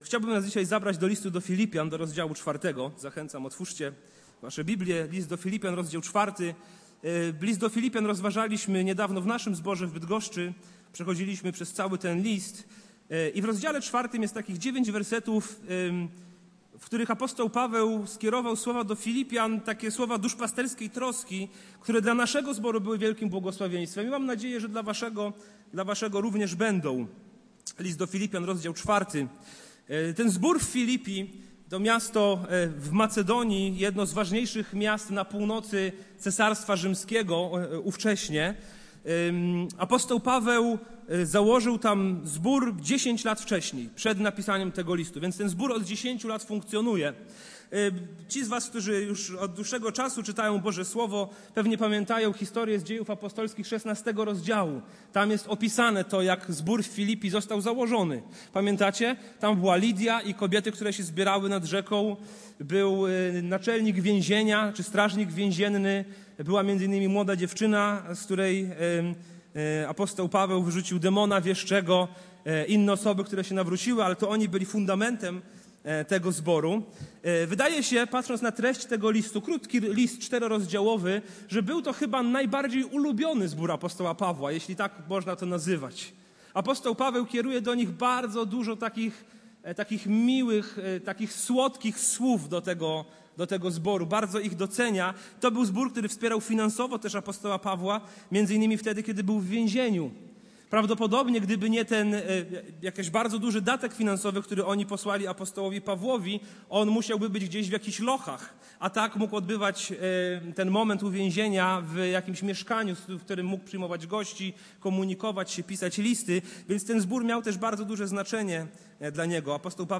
Kazanie
wygłoszone na nabożeństwie w niedzielę 28 lipca 2024